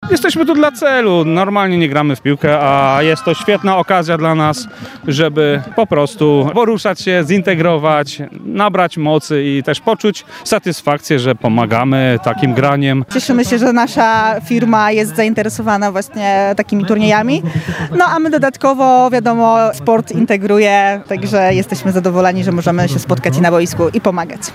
– Wynik nie ma znaczenia, najważniejsza jest integracja i cel, dla którego gramy – mówią uczestnicy zawodów.